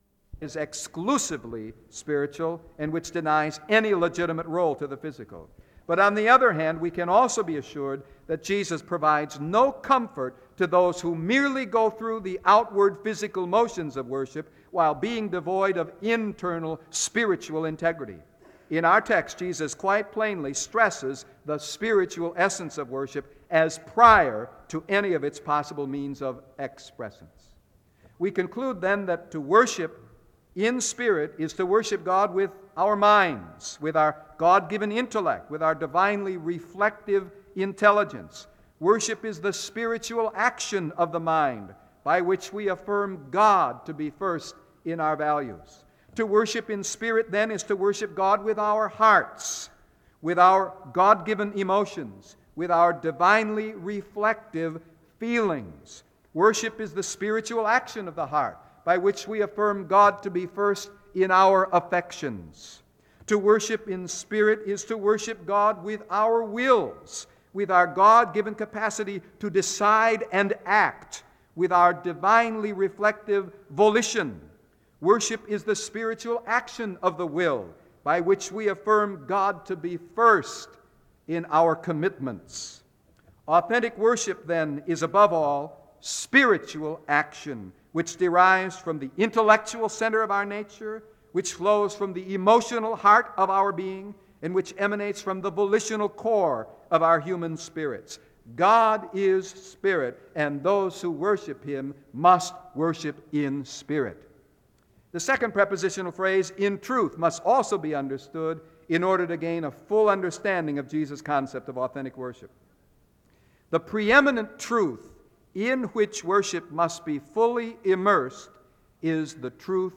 SEBTS Adams Lecture
• Wake Forest (N.C.)